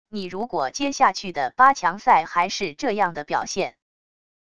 你如果接下去的八强赛还是这样的表现wav音频生成系统WAV Audio Player